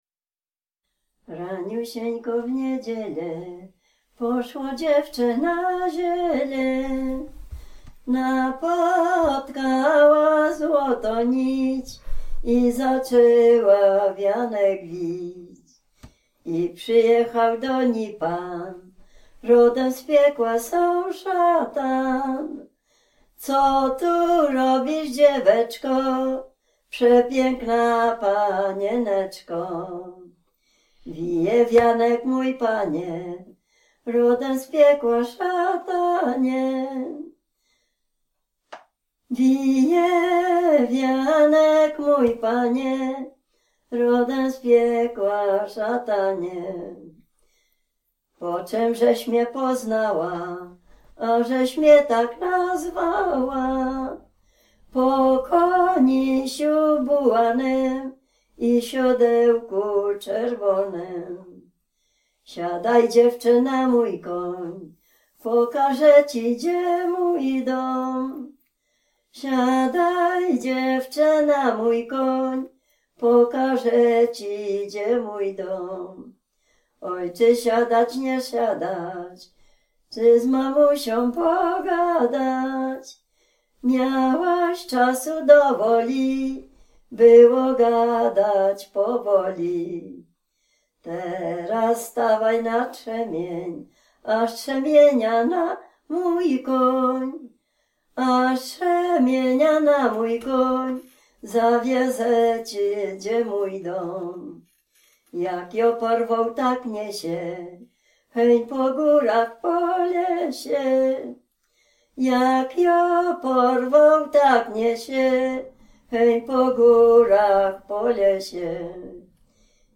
Dolny Śląsk, powiat bolesławiecki, gmina Nowogrodziec, wieś Zebrzydowa
Ballada
ballady dziadowskie